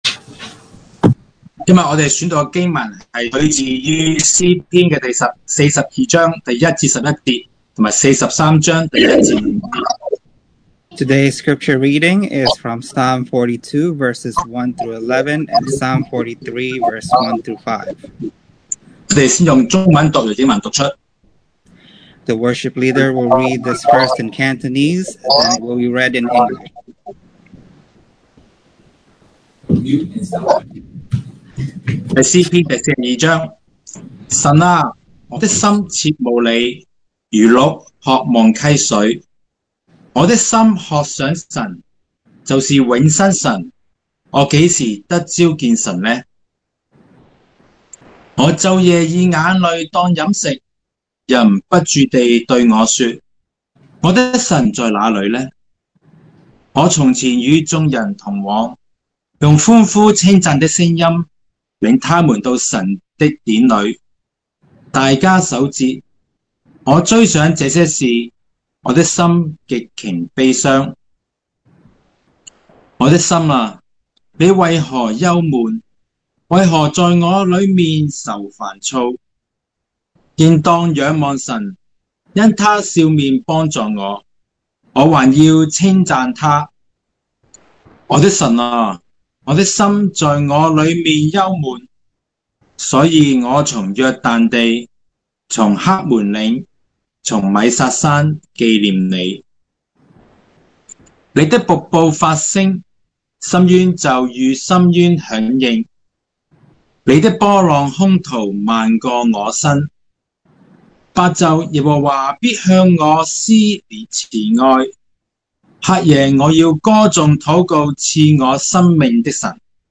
2020 sermon audios
Passage: Psalm 42:1-11, Psalm 43:1-5 Service Type: Sunday Morning